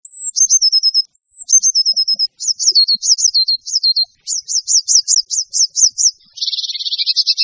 En cliquant ici vous entendrez le chant de la Mésange bleue.